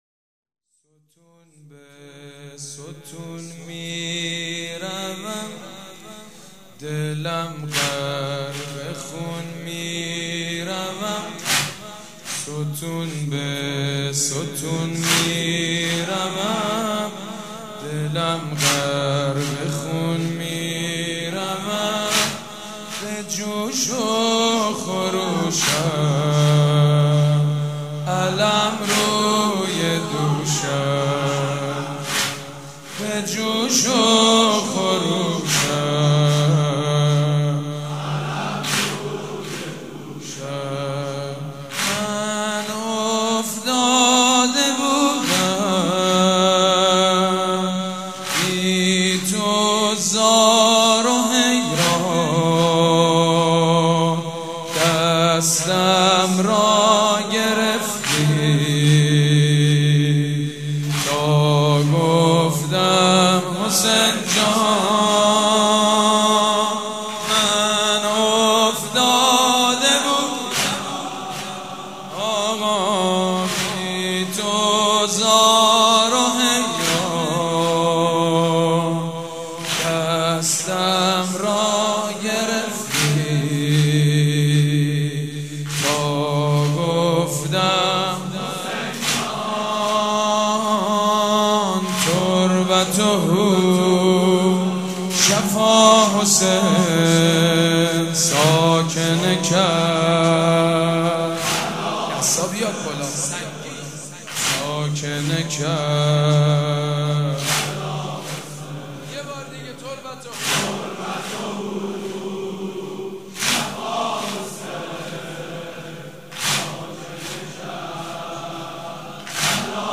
مداح
مراسم عزاداری شب ‌پنجم